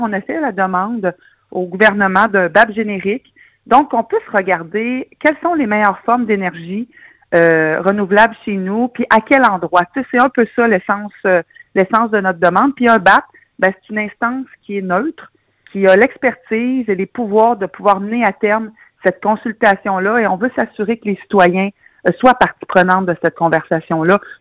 La mairesse, Geneviève Dubois, a apporté des éclaircissements à ce sujet alors que la demande a été envoyée aux différents paliers de gouvernement ainsi qu’au député Donald Martel.